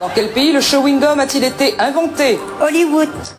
Play, download and share Maillon Faible Chewing original sound button!!!!
maillon-faible-chewing.mp3